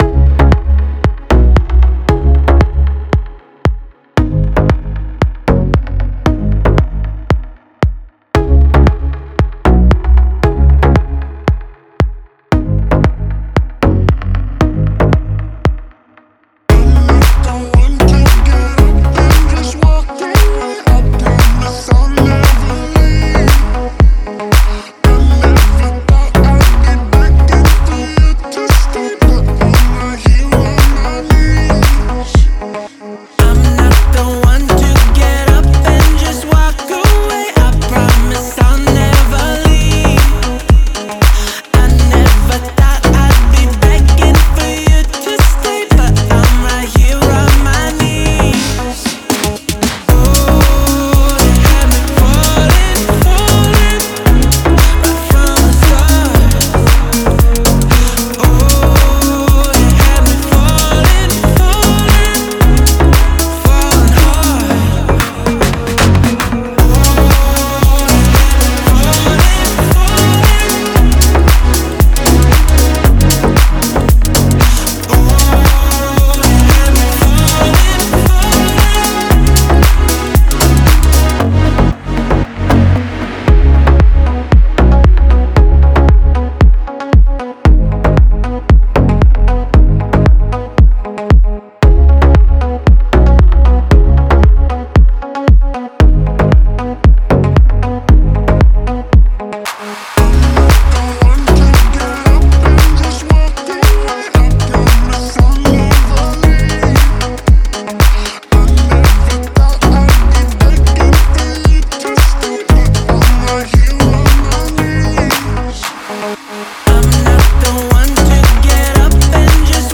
это завораживающая трек в жанре EDM